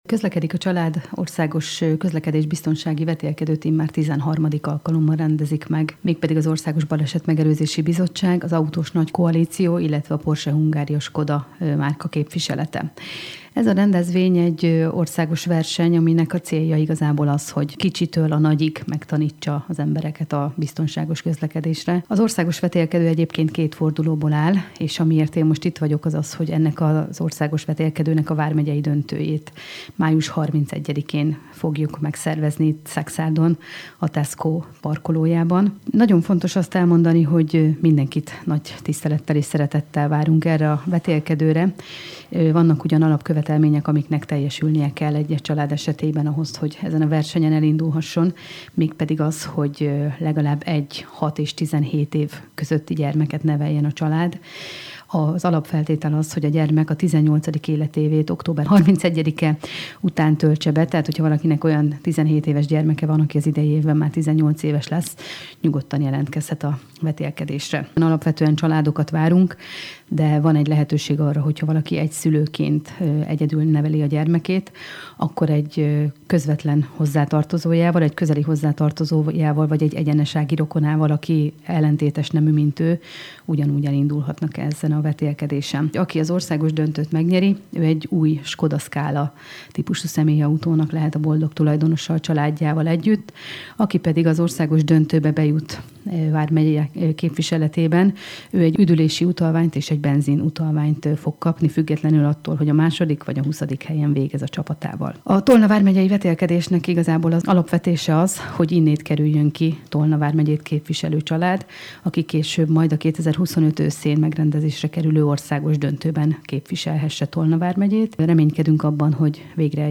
A polgármester válaszol